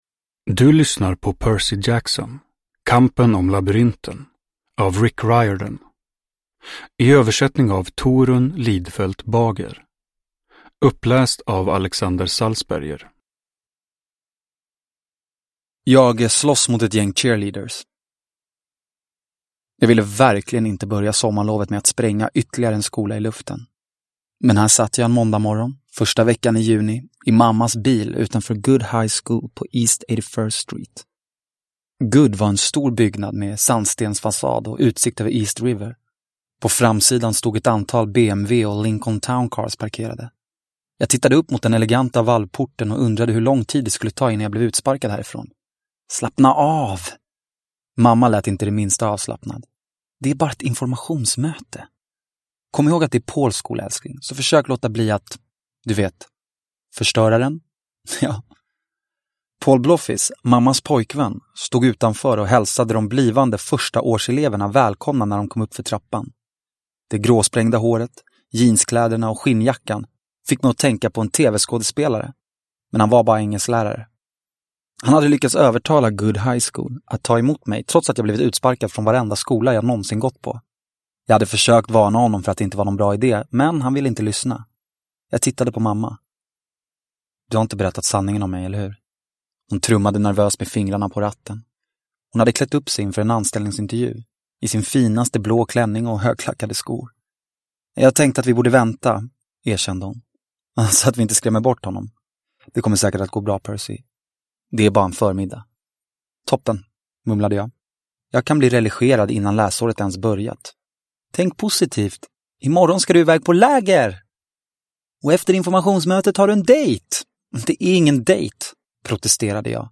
Kampen om Labyrinten – Ljudbok – Laddas ner